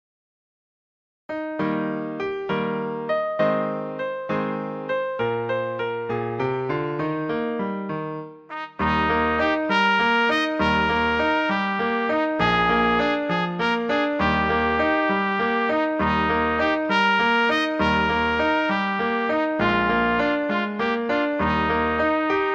Přednesová skladba pro trubku ( 2 variace )